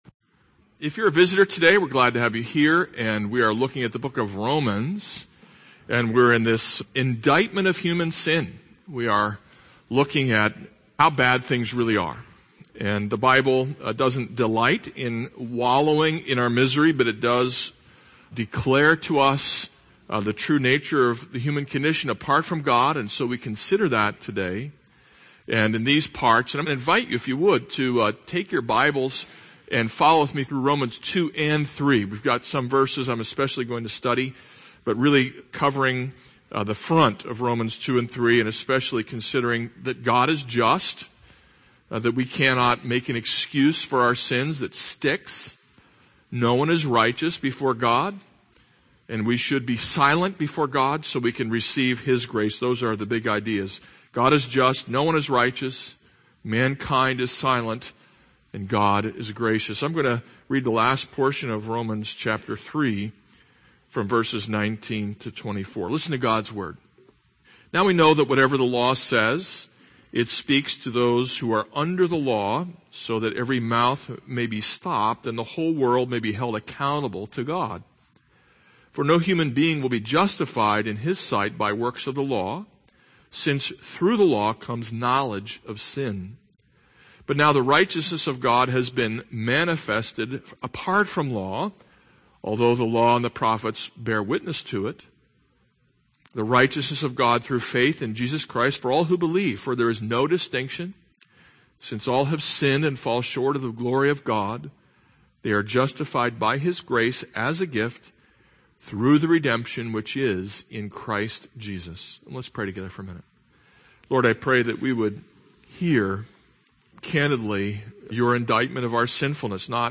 This is a sermon on Romans 3:19-24.